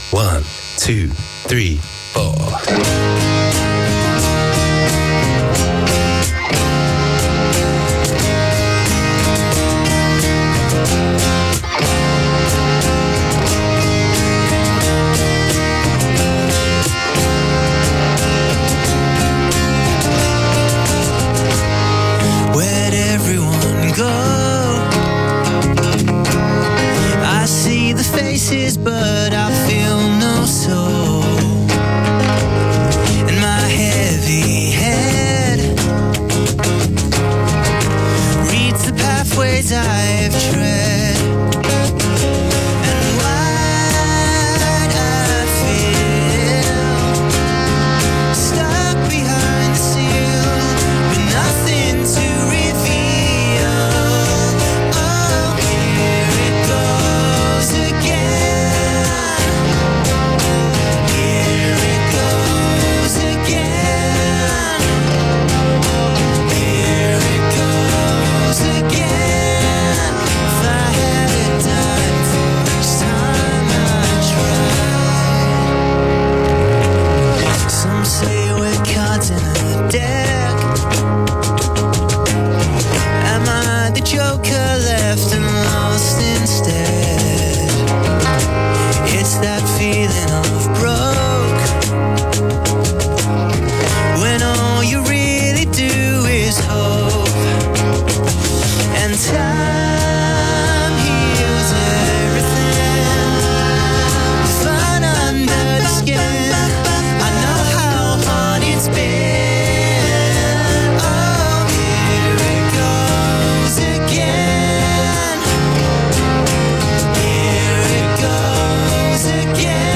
recorded and broadcast live